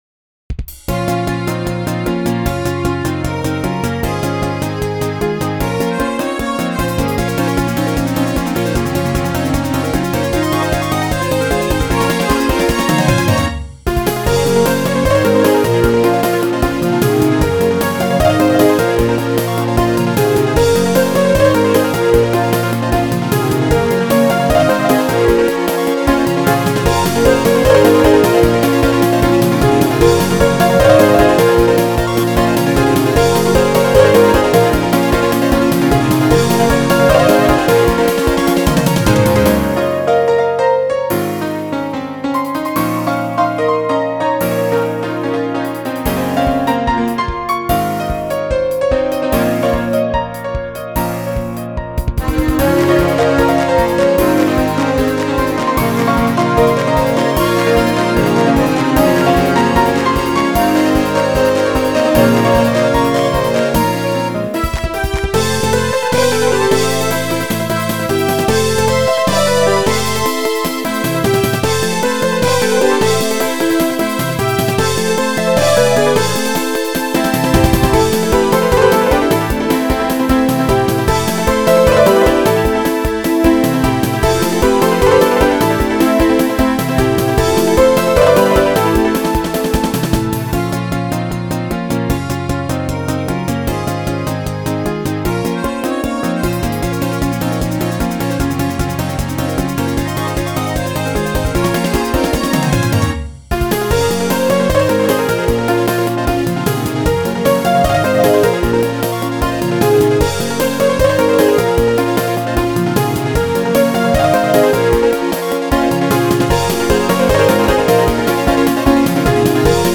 由Roland Sound Canvas 88Pro实机录制